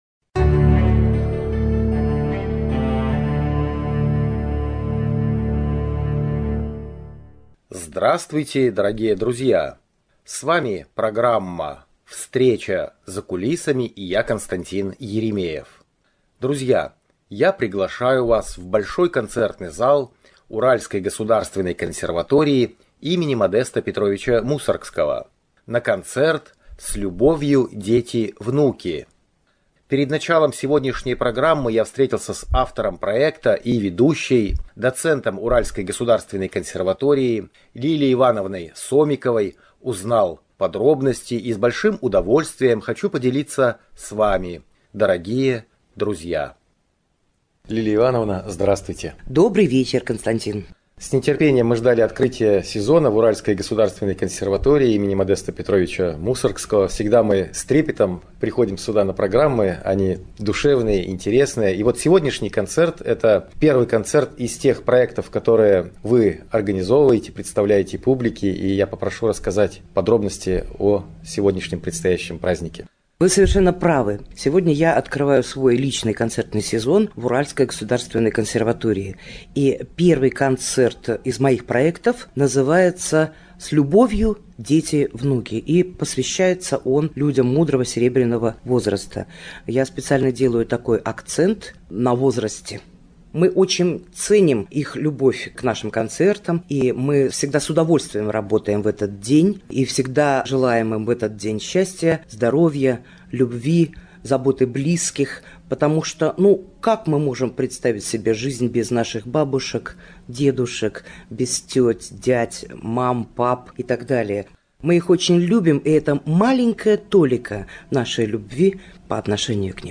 Концерт
koncert_s_lyubovyu_deti_vnuki.mp3